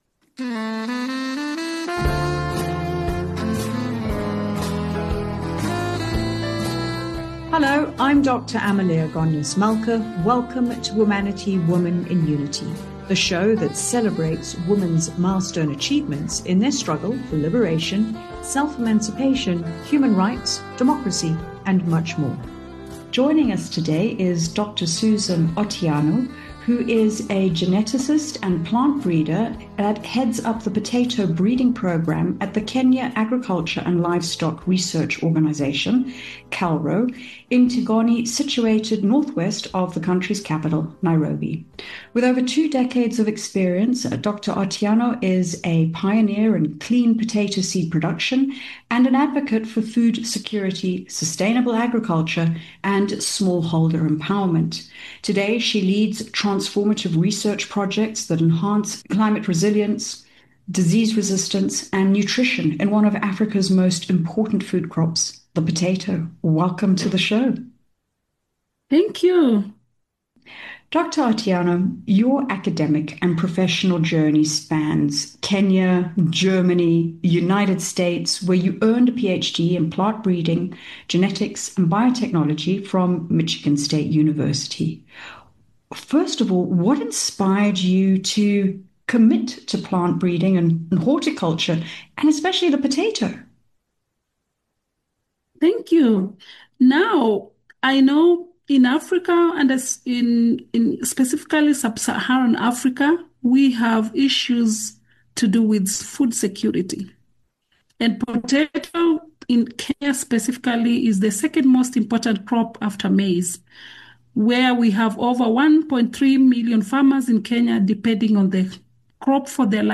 She also highlights varieties with short cooking times, which conserve energy and time for resource-poor farmers who often rely on firewood. She explains the rigorous, multi-year process of potato breeding, which can take up to twelve years for conventional methods, and how farmer feedback is crucial in selecting desirable traits like disease tolerance, high yield, and quick cooking time.